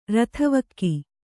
♪ rathavakki